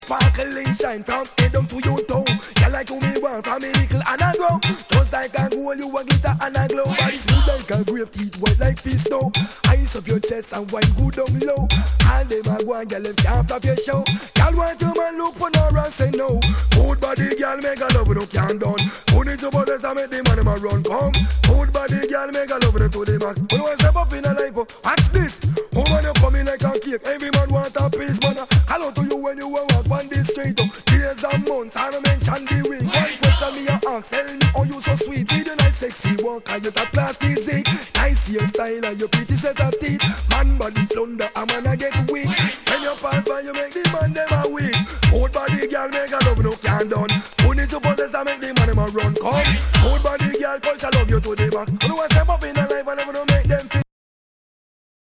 類別 雷鬼
ナイス！レゲエ！